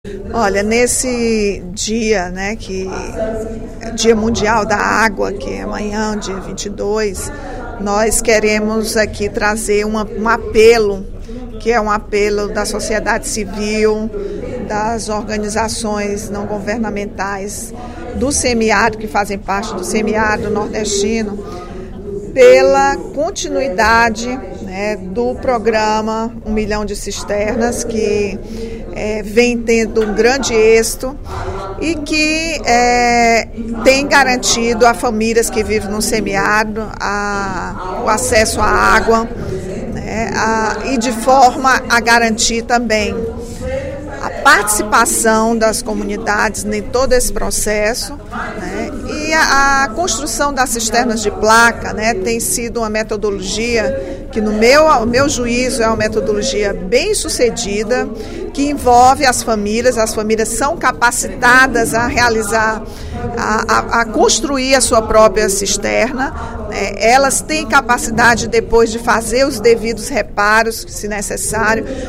A deputada Rachel Marques (PT) destacou na sessão plenária, desta quarta-feira (21/03), que amanhã será comemorado o Dia Mundial da Água, data instituída pela Organização das Nações Unidas (ONU).